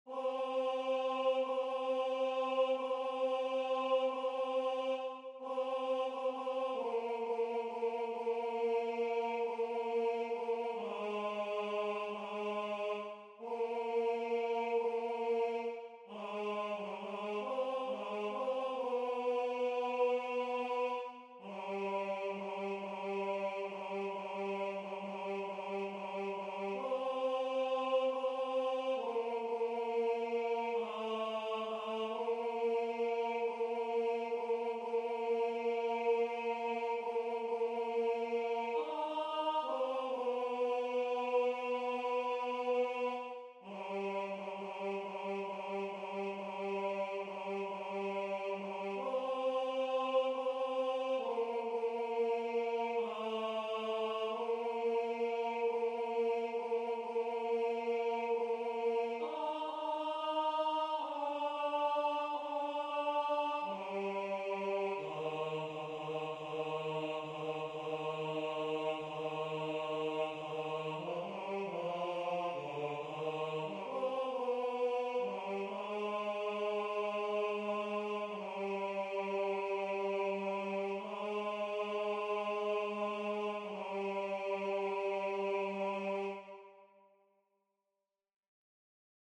Rendu voix synth.
Tenor